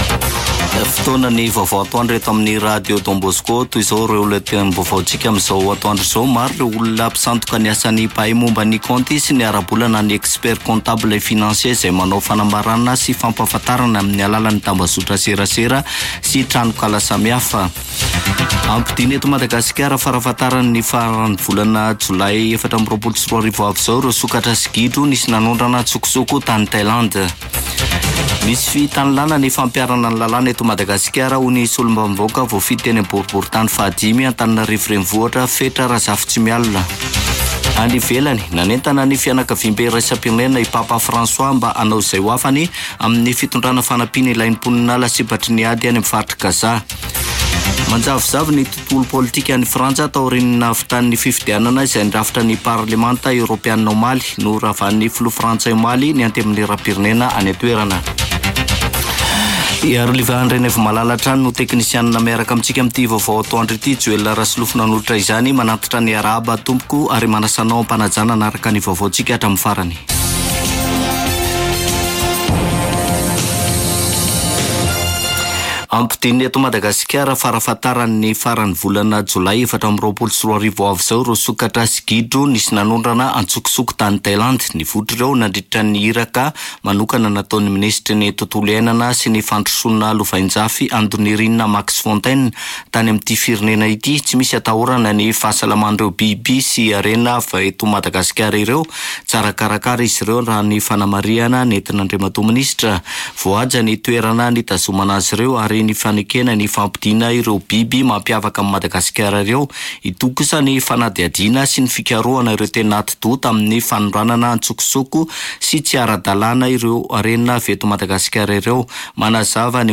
[Vaovao antoandro] Alatsinainy 10 jona 2024